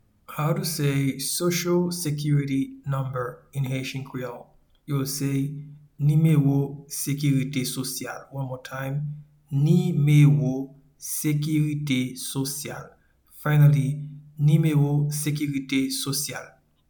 Pronunciation and Transcript:
Social-security-number-in-Haitian-Creole-Nimewo-sekirite-sosyal.mp3